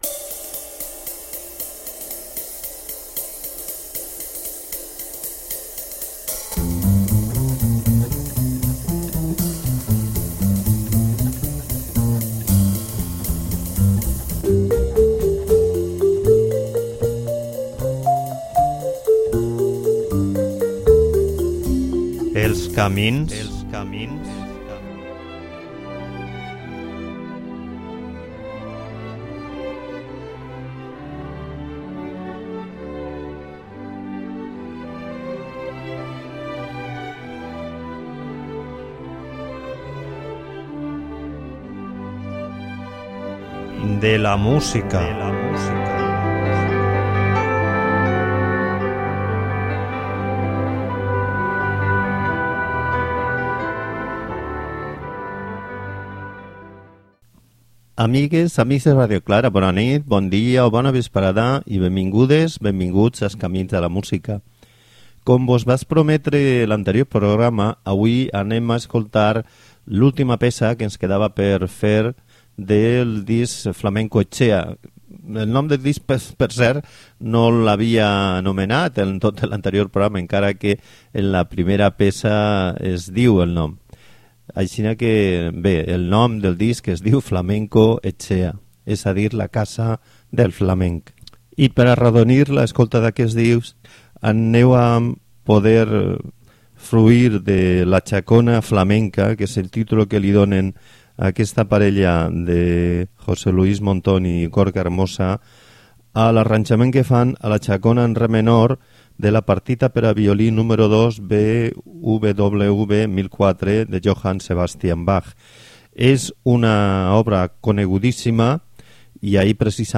Per completar el programa vos posaré música de gent que està al voltant d'ell o que ha coŀlaborat al disc i que també fa músiques (relativament) infreqüents, però que van en el camí que els nostres dos protagonistes volen caminar: el de trobar en les seues "veus" una renovació de les músiques populars, tradicionals, aportant les seues visions personals, innovadores, però que pretenen que siguen "reconeixibles", que es puguen detectar les seues arrels.